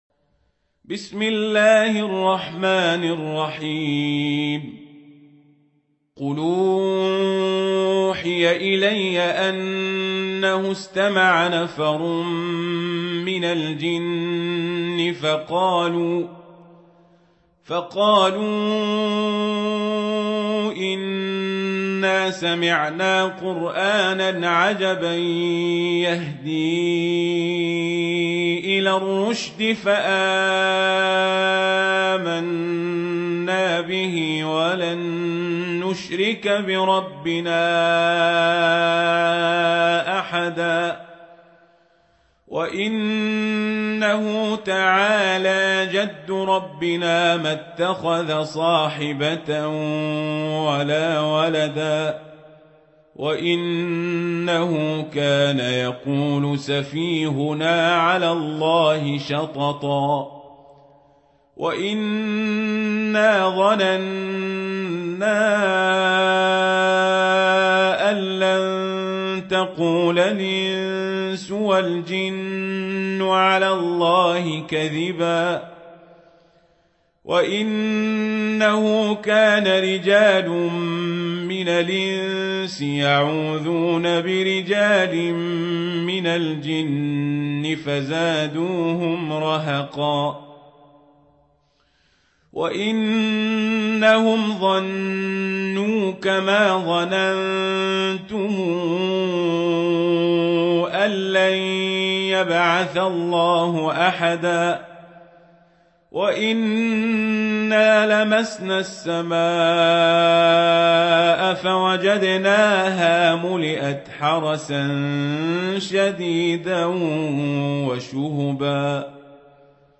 سورة الجن | القارئ عمر القزابري